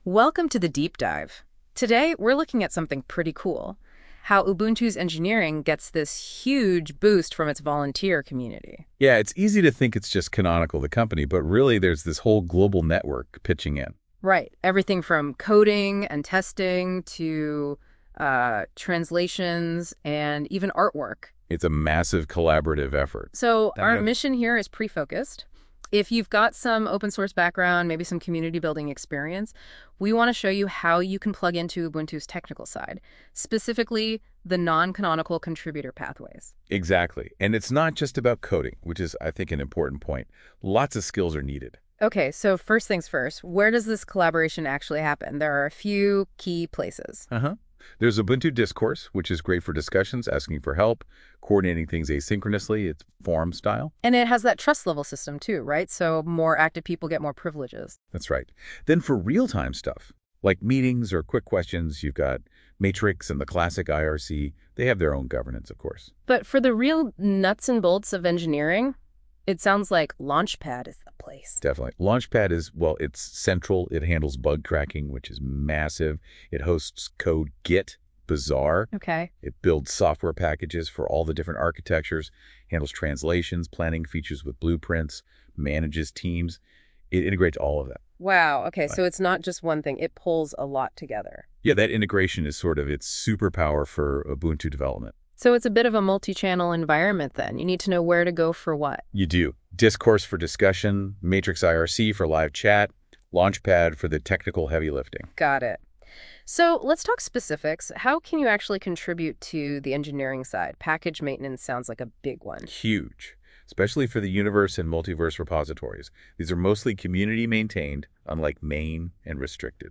It also generated an audio “podcast” style version, which is kinda wild, and amusing.